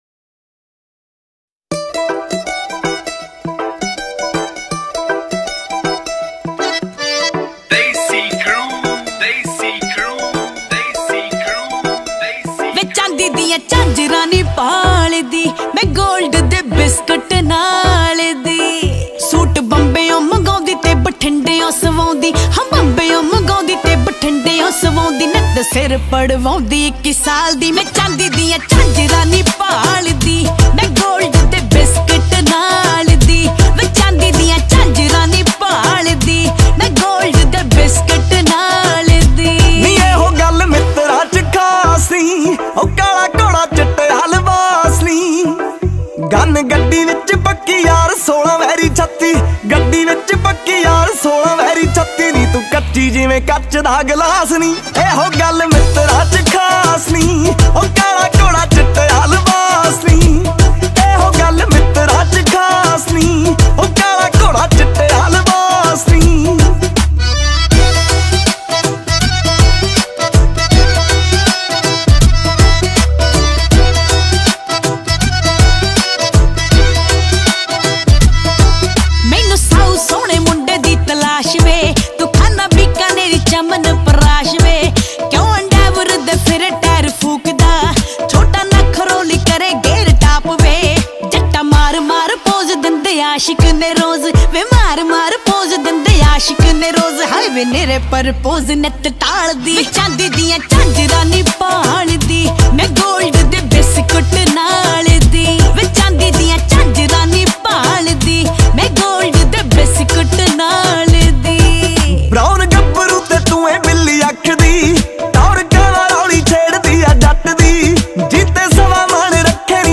Category :Punjabi Music